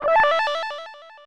checkpoint.wav